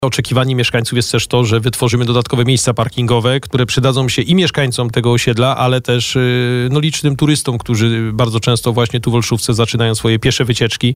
– Można powiedzieć, że już formalnie wystartowaliśmy. Niedługo zobaczymy tam ekipy budowlane – mówił na naszej antenie Adam Ruśniak, zastępca prezydenta Bielska-Białej pytany o kontynuację remontu ul. Pocztowej, tym razem jeszcze z fragmentem ul. Startowej.